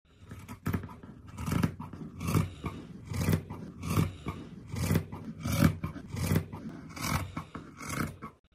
Tiếng Kéo Cắt Vải, Kéo xén mảnh vải
Thể loại: Tiếng động
Description: Đây là âm thanh nhấn mạnh sự tách rời, cắt đứt của vật liệu... Nó tạo ra một cảm giác thực tế, mang lại trải nghiệm giống như bạn đang ở trong cảnh đó. Với hiệu ứng âm thanh này, mỗi cú cắt, mỗi xé mảnh vải trở nên sống động hơn, giúp thúc đẩy câu chuyện mà video muốn kể.
tieng-keo-cat-vai-keo-xen-manh-vai-www_tiengdong_com.mp3